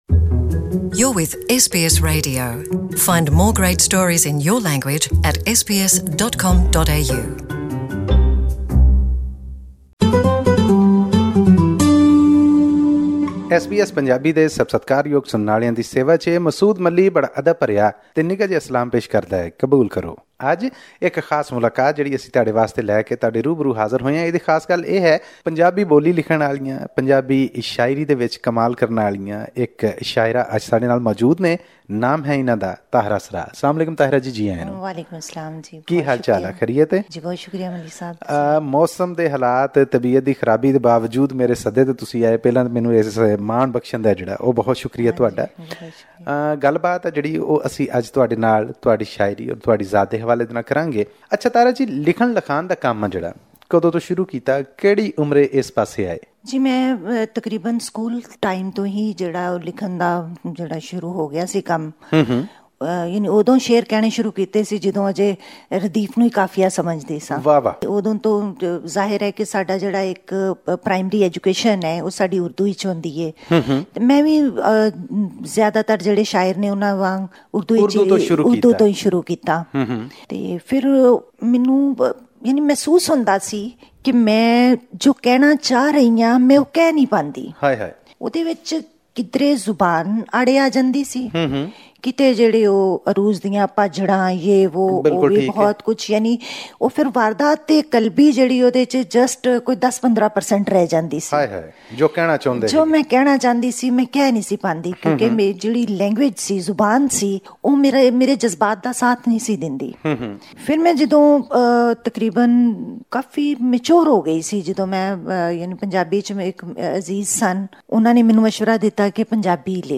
Listen to this interview in Punjabi by clicking on the player at the top of the page.